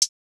Closed Hats
Hi hat maestro.wav